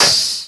armRetractionWhiff.wav